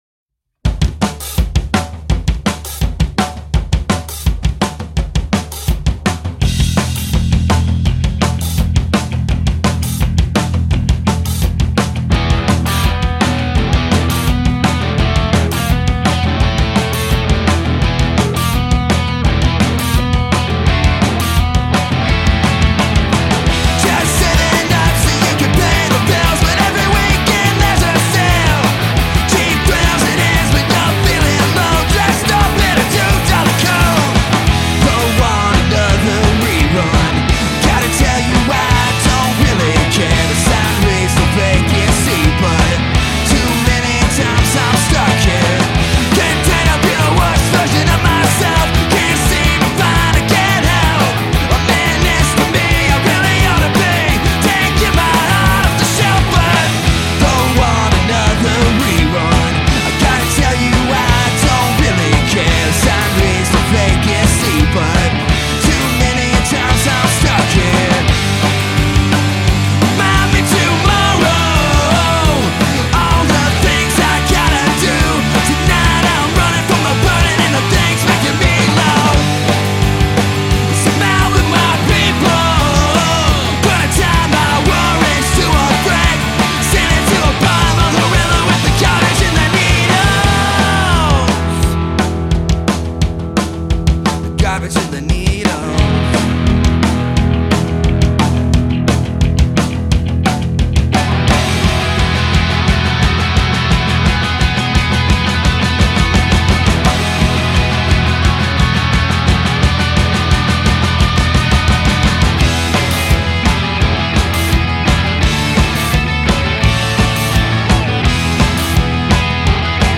bass and drums